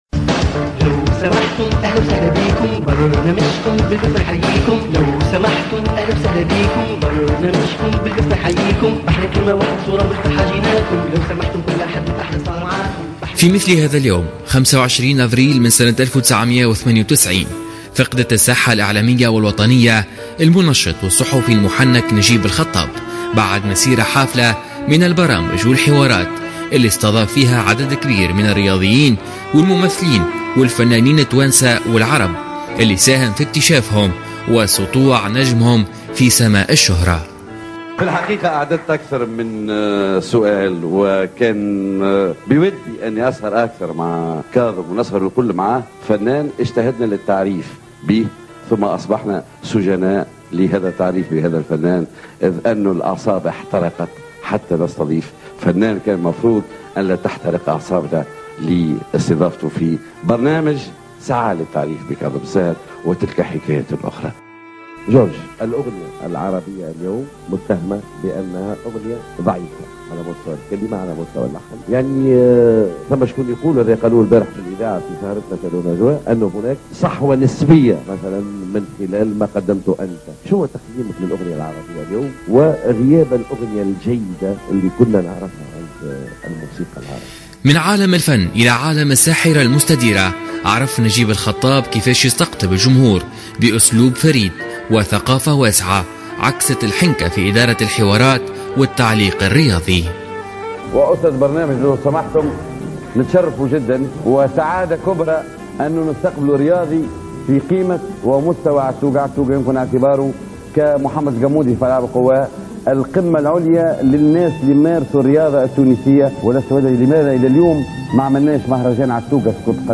ميكرو الجوهرة "اف ام" رصد آراء عدد من المواطنينن في شوارع مدينة سوسة فتفاجئنا بسقوط ذكرى نجيب الخطاب من ذاكرة التونسيين بعد 18 سنة من رحيله و اكتشفنا أن بعض المُواطنين نسوا نجيب الخطاب و رفضوا حتى التعليق على ذكرى وفاته مِتعّللين بمشاغل الحياة و مواضيع يعتقدون أنها الأهم في الوقت الراهن .